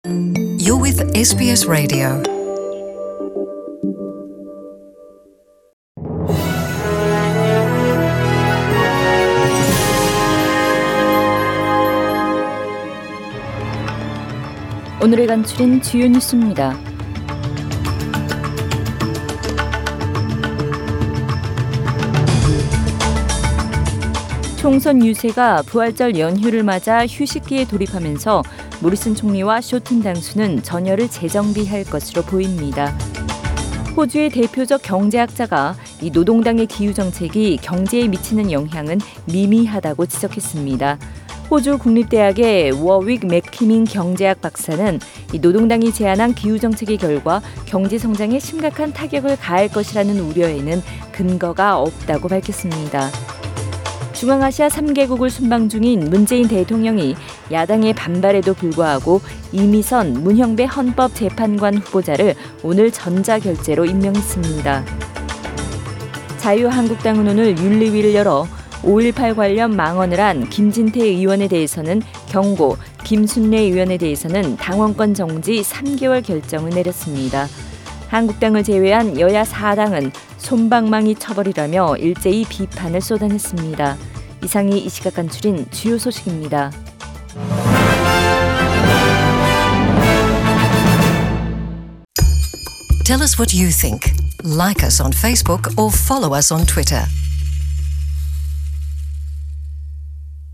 SBS 한국어 뉴스 간추린 주요 소식 – 4월 19일 금요일